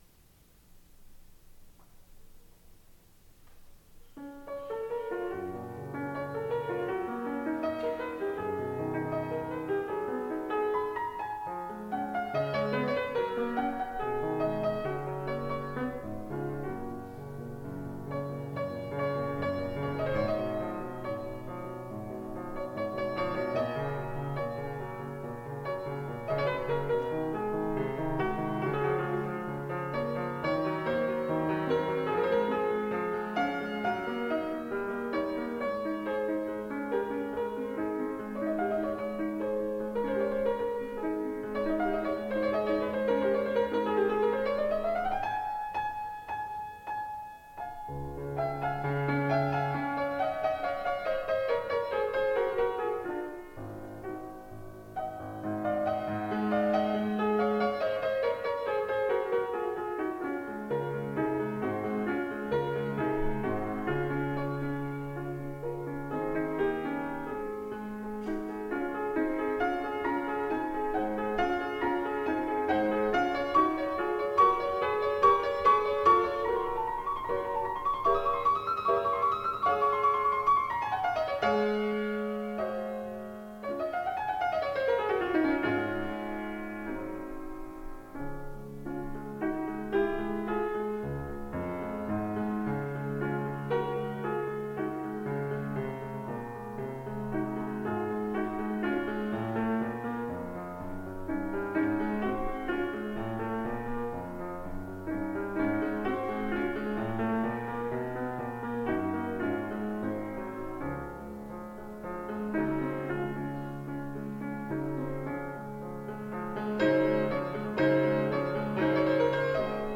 Recital - April 10, 1994